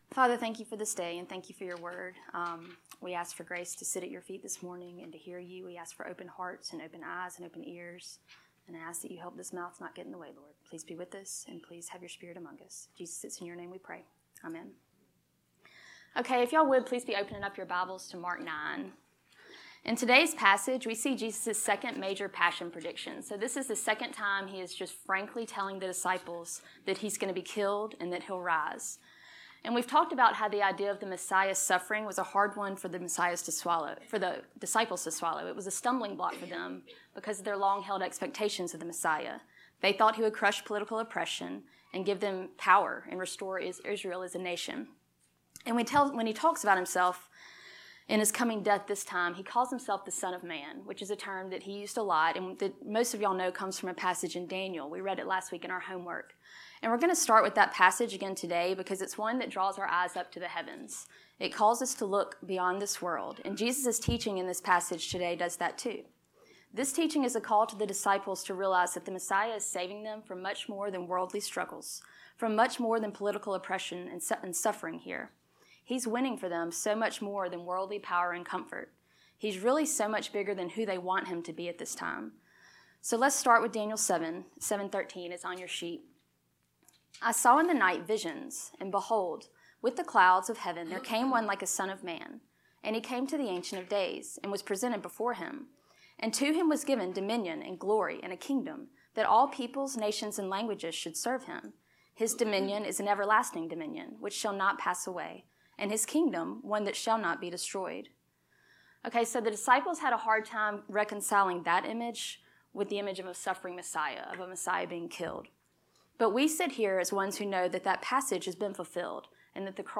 Lesson 14